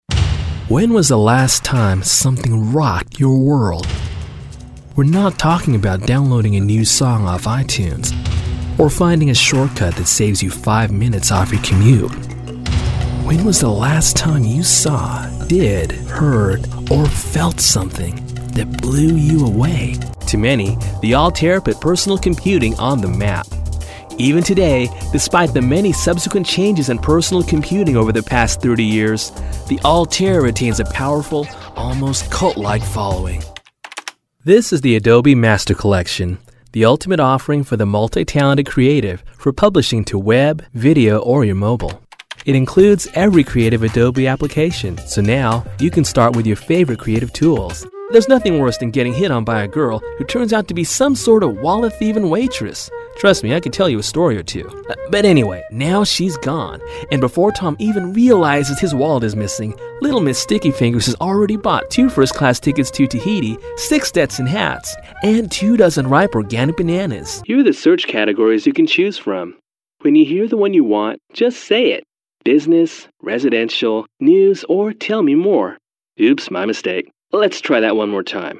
Young Male Talent: I do commercials and narration.
Sprechprobe: eLearning (Muttersprache):